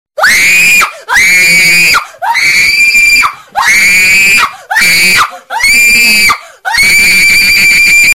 голосовые
очень громкие
крики
вопли
визг